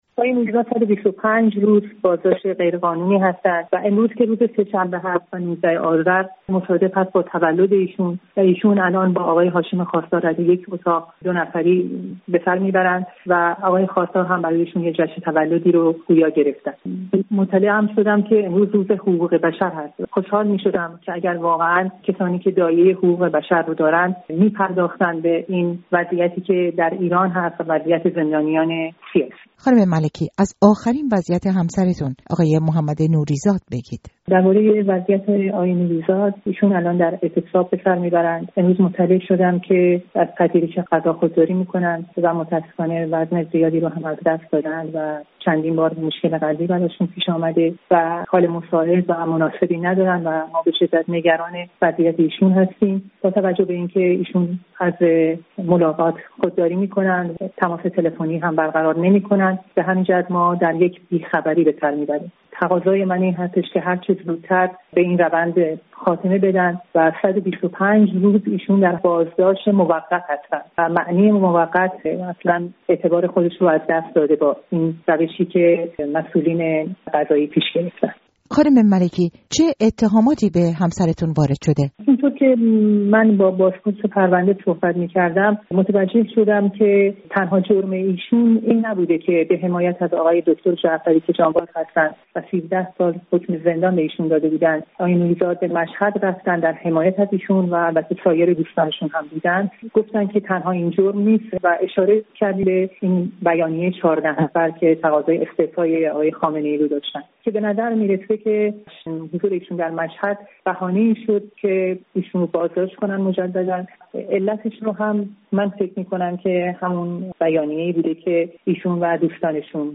گفت‌وگویی با رادیوفردا